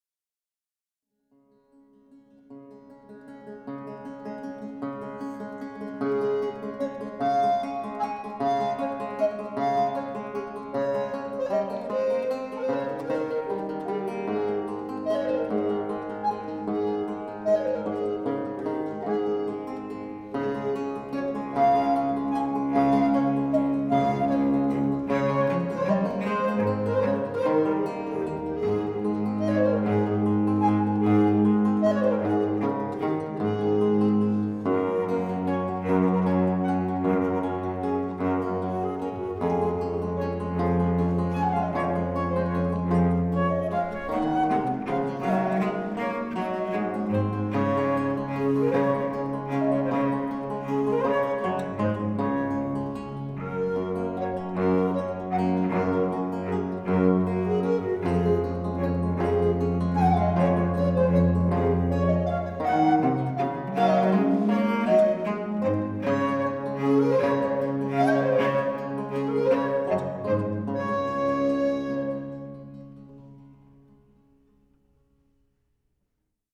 zobcové flétny
traverso
barokní violoncello
loutny
Nahrávky z koncertů v Ostravě - Porubě a Velké Polomi, 28. června 2014